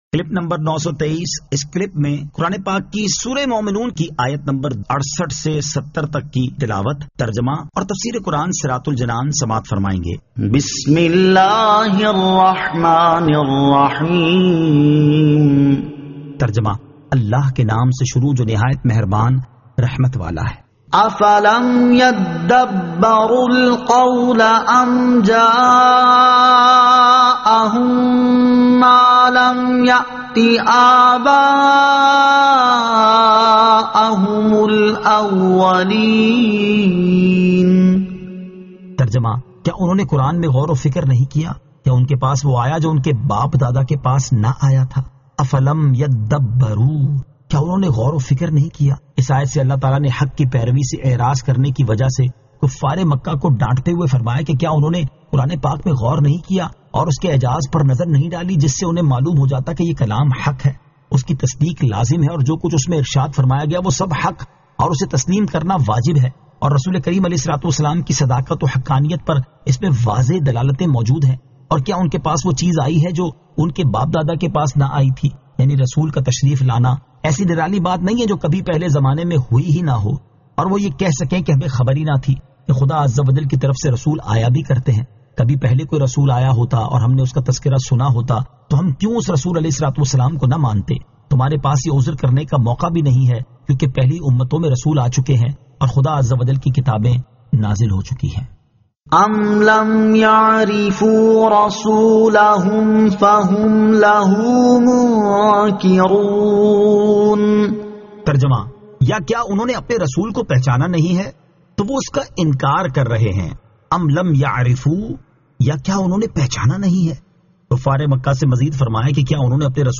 Surah Al-Mu'minun 68 To 70 Tilawat , Tarjama , Tafseer